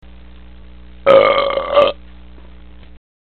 Rülpsen 3 56 KB 1555 Sound abspielen!
ruelpsen3.mp3